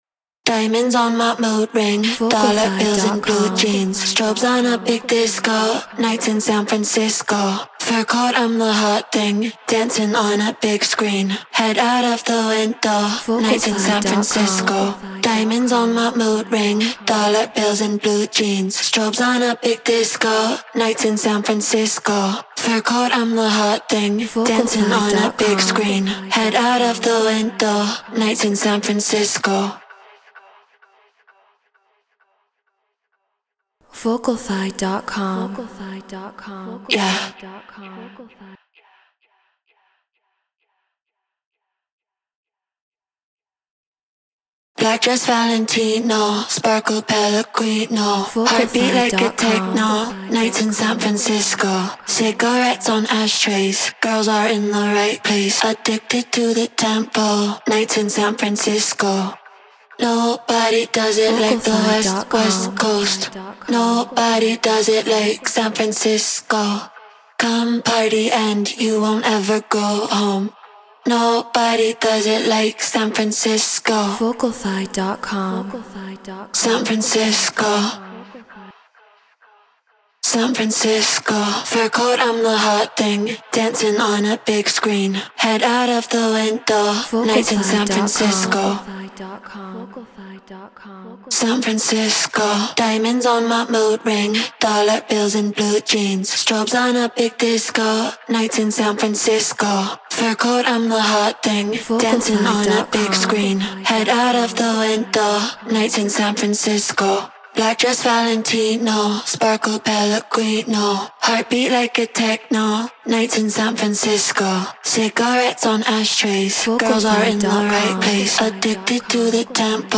Tech House 130 BPM Emin
Shure SM7B Scarlett 2i2 4th Gen Ableton Live Treated Room